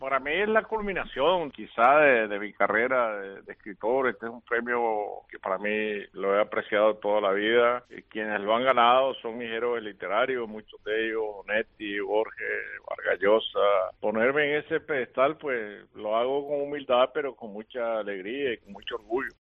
En declaraciones a la Cadena COPE instantes después de conocer que había sido galardonado con el Cervantes, Sergio Ramírez admitía que para él "es la culminación de mi carrera de escritor. Es un Premio que lo he apreciado toda la vida y quienes lo han ganado son sido mis héroes literarios, Onetti, Vargas Llosa, Borges... Ponerme en ese pedestal, lo hago con mucha alegría".